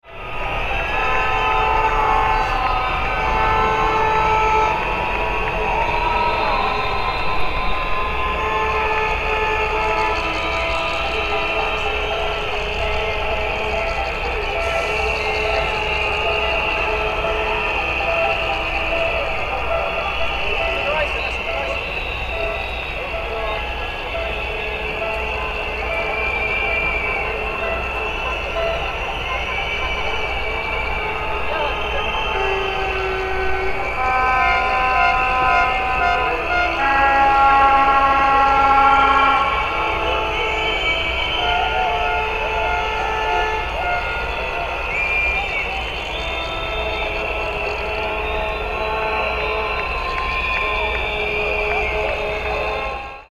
High-Energy Street Protest Ambience – Crowd Chanting & Air Horns Sound Effect
Authentic street protest ambience featuring loud air horns, crowd chanting, whistles, and intense public demonstration atmosphere.
Genres: Sound Effects
High-energy-street-protest-ambience-crowd-chanting-air-horns-sound-effect.mp3